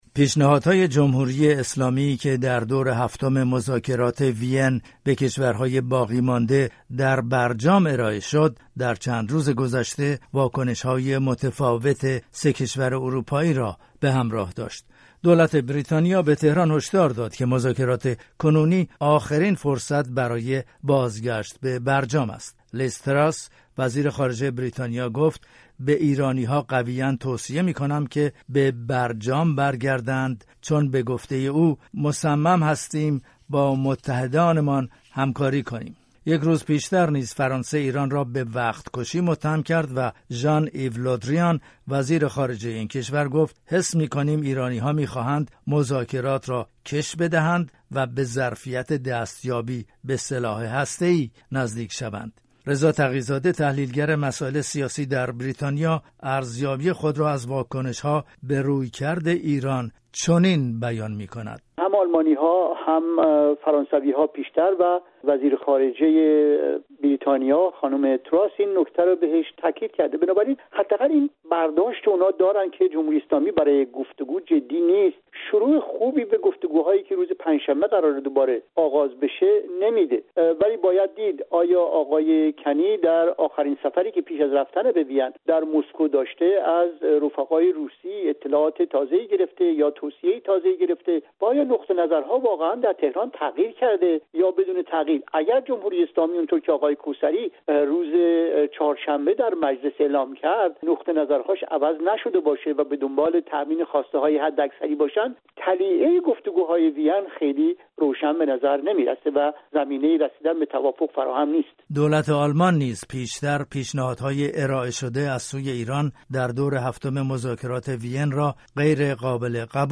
گفت‌وگو با دو کارشناس درباره دور تازه مذاکرات احیای برجام در وین